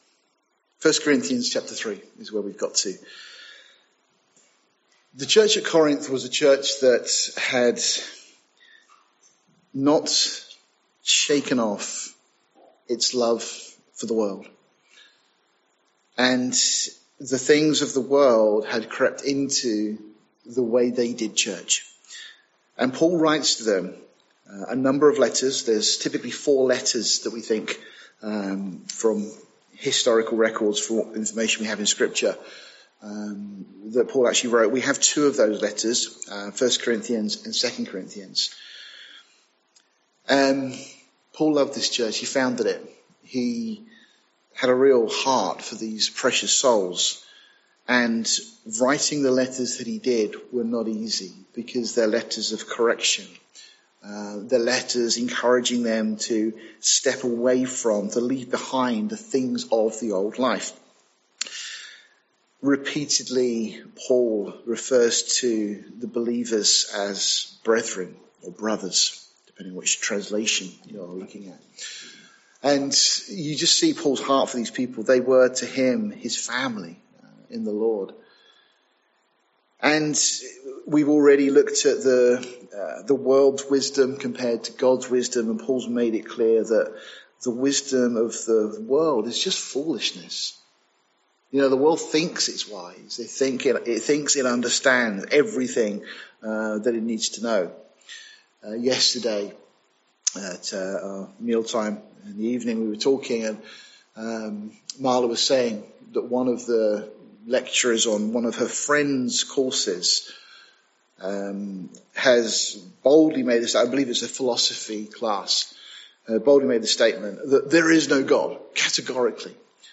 This study of 1 Corinthians chapter 3 was recorded on 22nd October 2023 at Calvary Portsmouth.